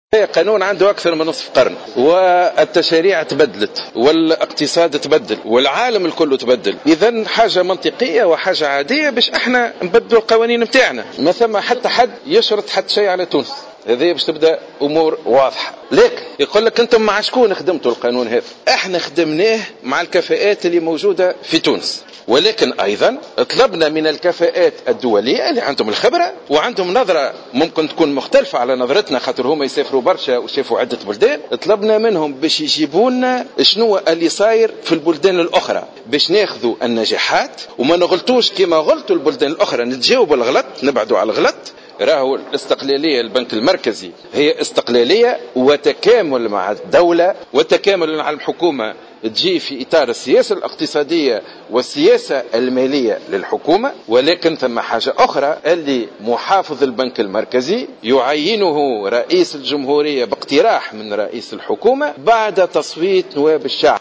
وقال شاكر في تصريح لمراسل "الجوهرة أف أم" على هامش الجلسة العامة لمجلس نواب الشعب المخصصة لمناقشة مشروع قانون النظام الأساسي للبنك المركزي إنه ليس بإمكان أي جهة فرض إملاءات على تونس، موضحا أن القانون شارك في إعداده عدد من الخبراء والكفاءات التونسية مع الاستعانة بكفاءات دولية.